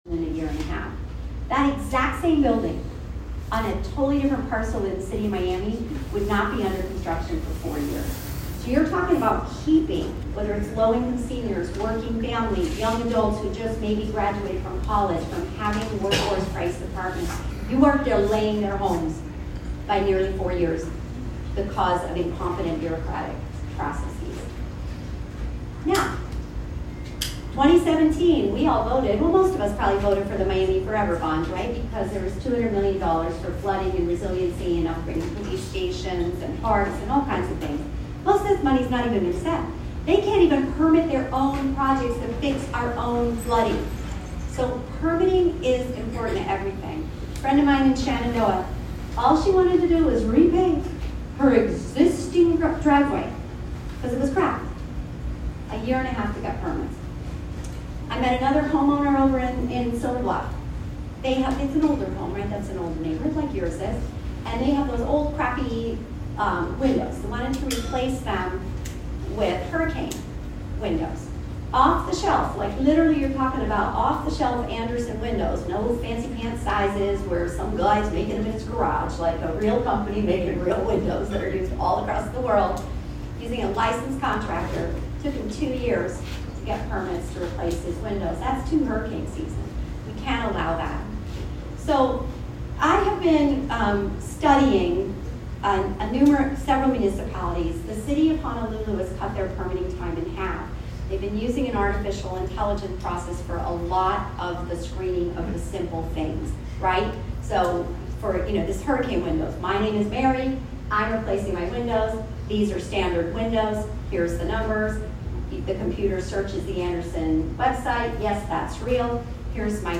After introductions, Eileen Higgins began speaking about the inefficiencies within the city.
We were fortunate to host Eileen Higgins at our September neighborhood meeting.